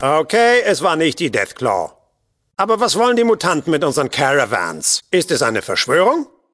in: Fallout: Audiodialoge Datei : BUTCH46.ogg Quelltext anzeigen TimedText Versionsgeschichte Diskussion Tritt unserem Discord bei und informiere dich auf unserem Twitter-Kanal über die aktuellsten Themen rund um Fallout!